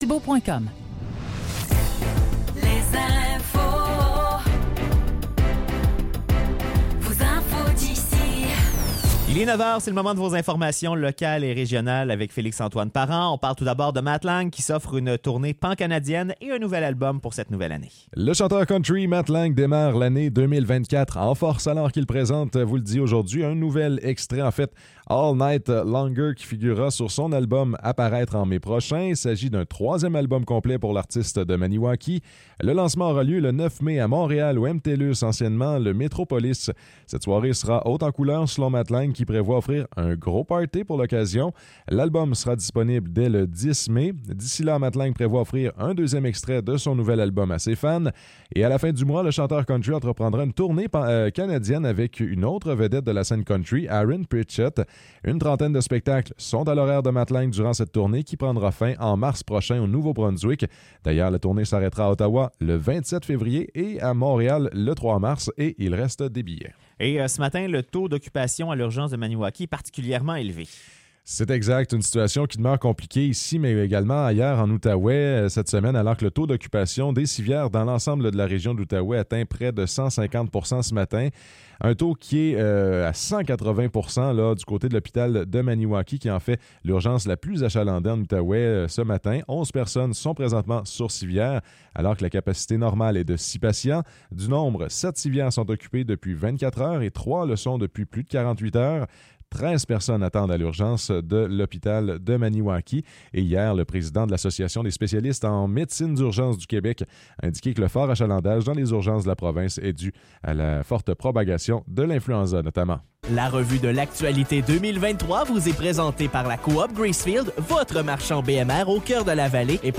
Nouvelles locales - 5 janvier 2024 - 9 h